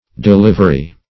Delivery \De*liv"er*y\, n.; pl. Deliveries.